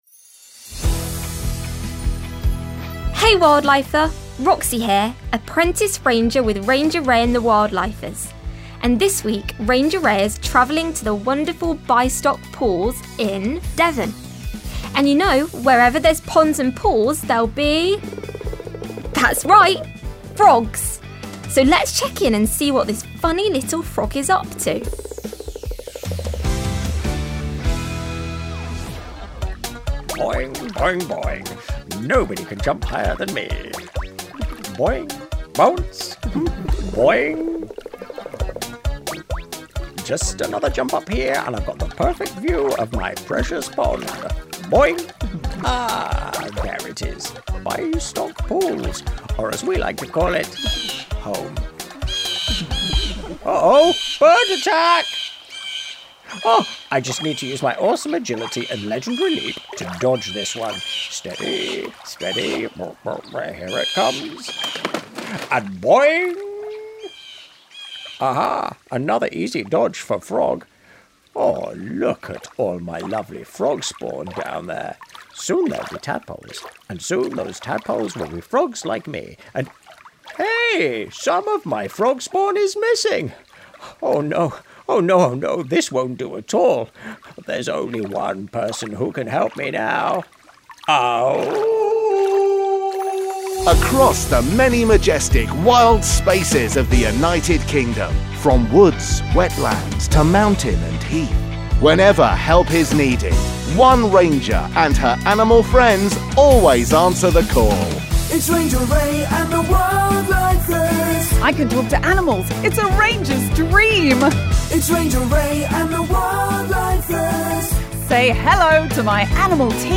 Other characters played by members of the ensemble.